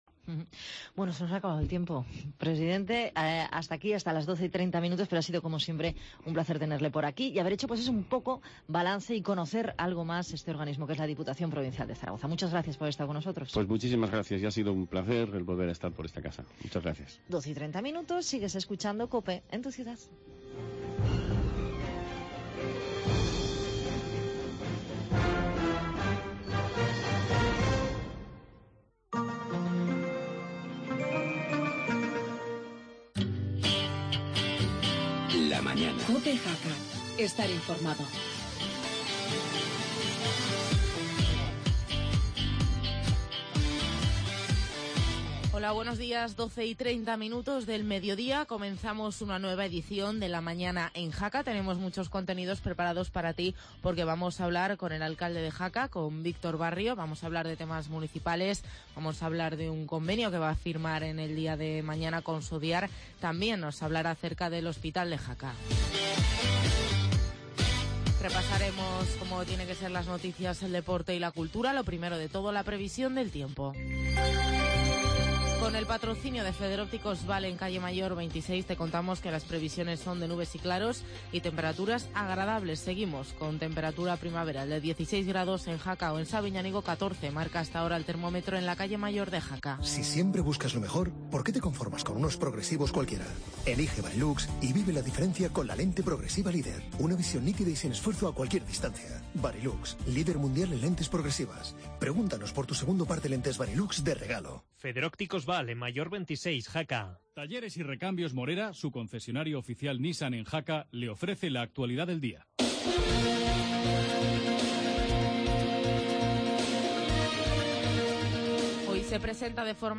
AUDIO: Actualidad con entrevista al alcalde de Jaca Víctor Barrio.